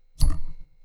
metalSlideOnMetal2.wav